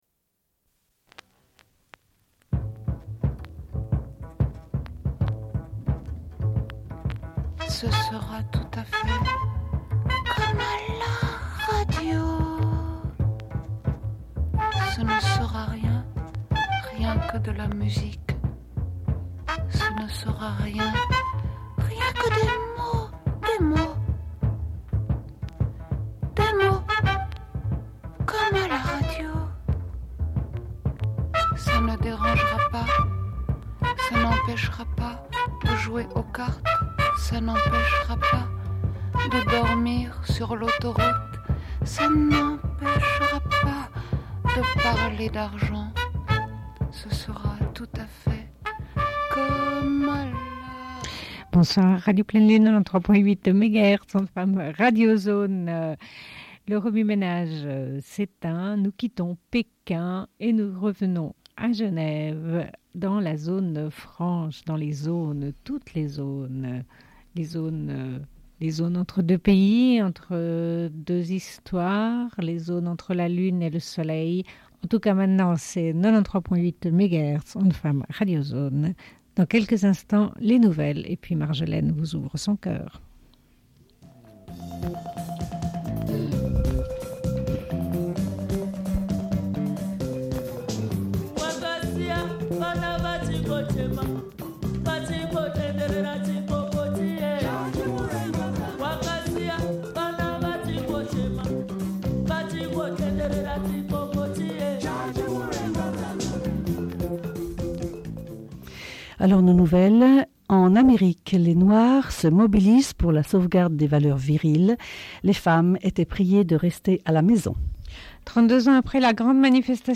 Bulletin d'information de Radio Pleine Lune du 18.10.1995 - Archives contestataires
Une cassette audio, face B